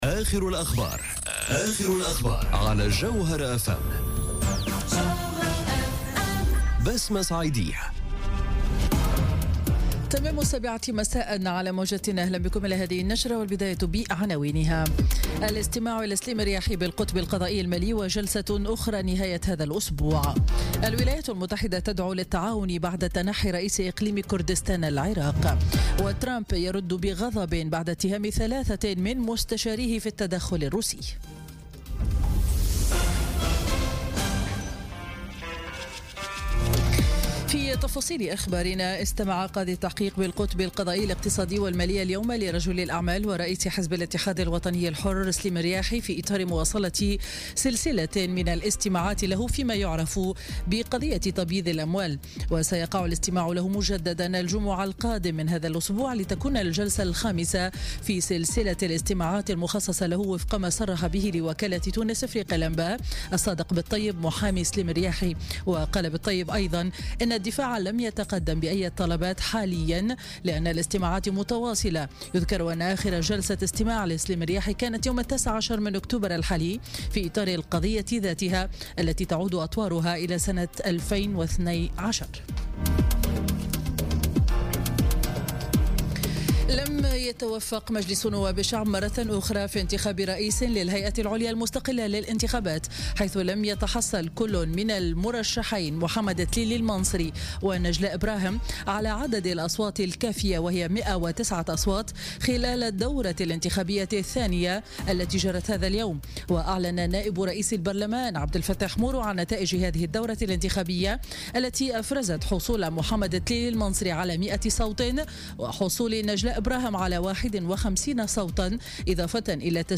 نشرة أخبار السابعة مساء ليوم الاثنين 30 أكتوبر 2017